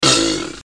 Chinese Alligator sound